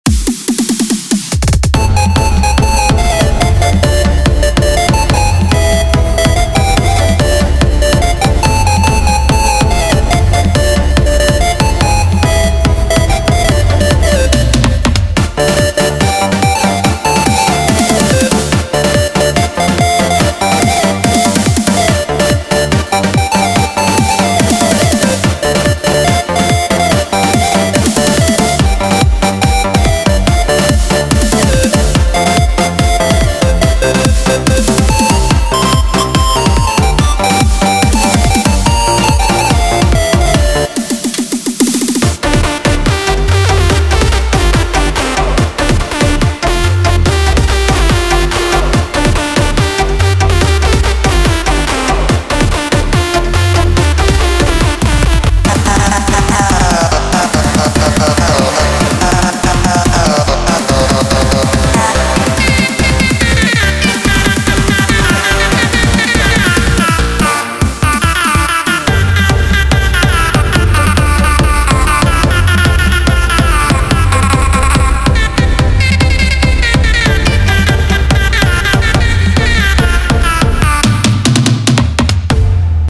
מקצבים לקורג